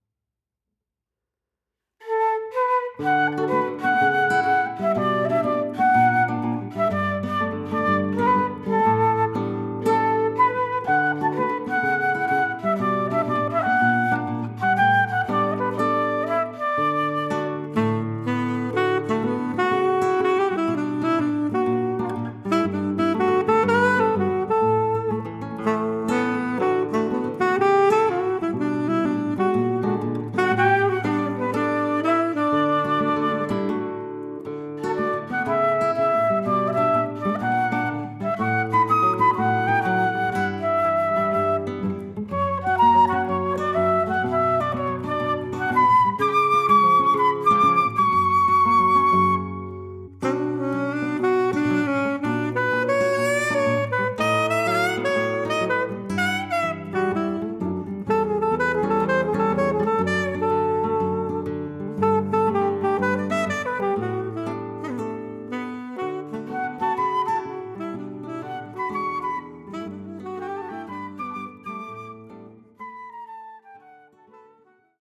instrumental